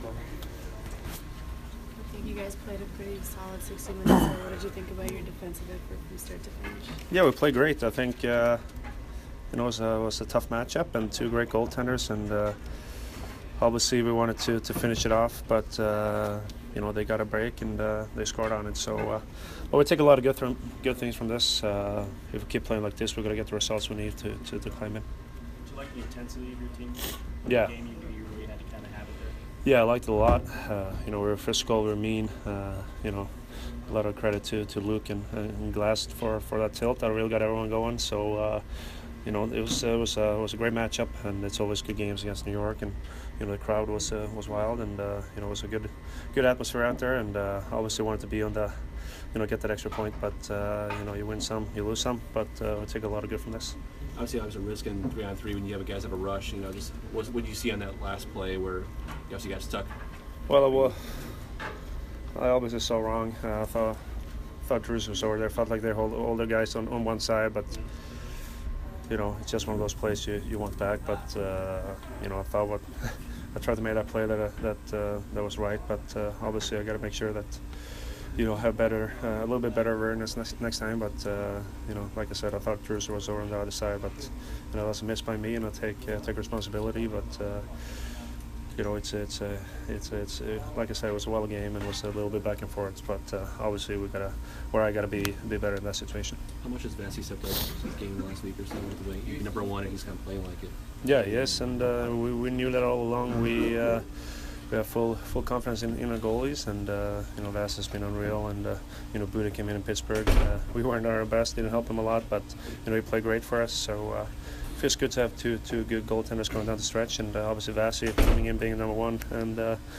Hedman Post Game 3 - 6